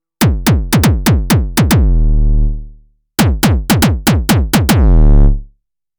It’s a matter of the taste of the person operating it :wink: Perhaps you mean something like this (syntakt, no fx drive):
That crunch comes direct out of the Syntakt :wink: